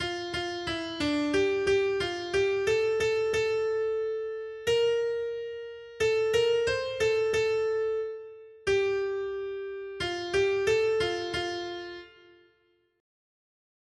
Noty Štítky, zpěvníky ol655.pdf responsoriální žalm Žaltář (Olejník) 655 Skrýt akordy R: U tebe je, Bože, pramen života. 1.